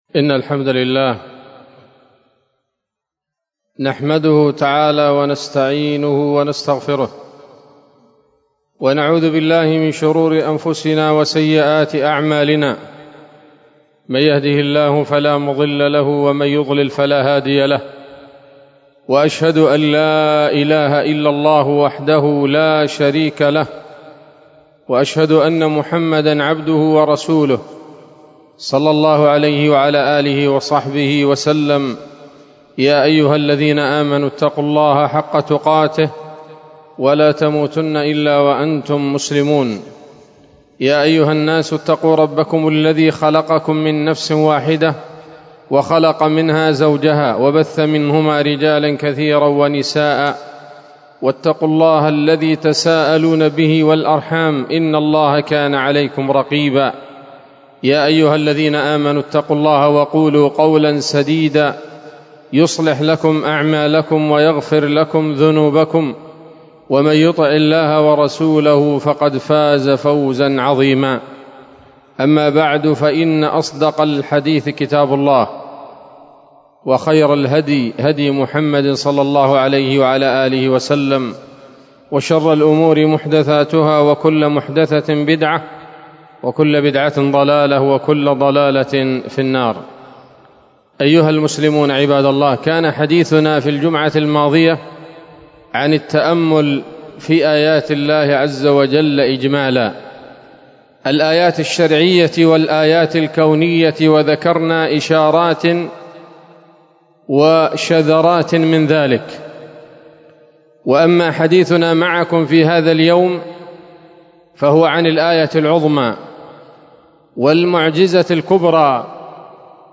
خطبة جمعة بعنوان: (( النبأ العظيم )) 20 شوال 1446 هـ، دار الحديث السلفية بصلاح الدين